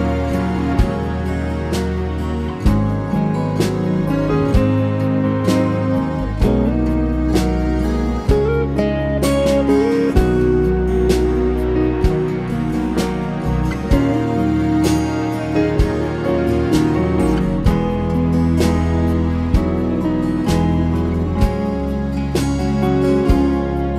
One Semitone Down Jazz / Swing 3:46 Buy £1.50